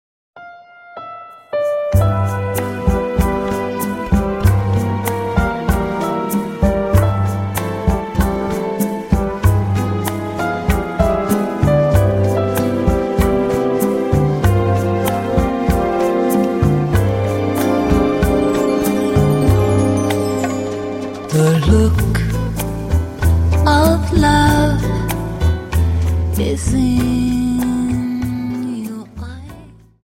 Dance: Rumba 24